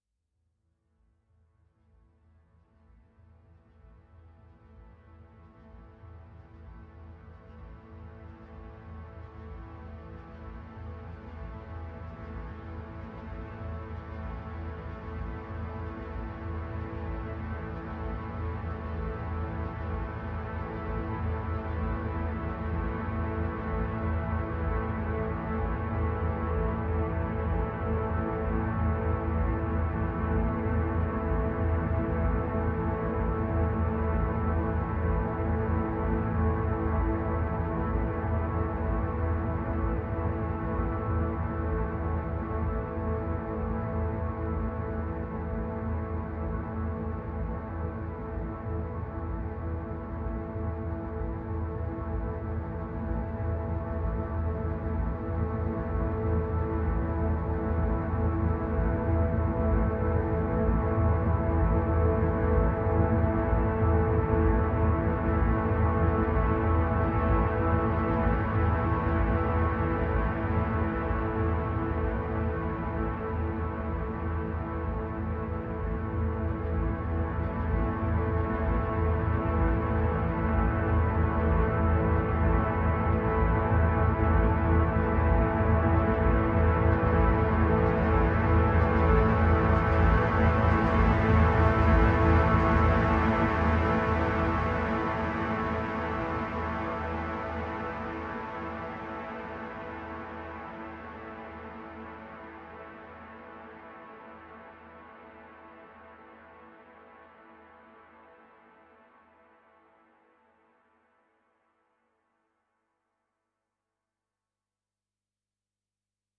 Trembling pads rise up with an intensifying mysterious feel.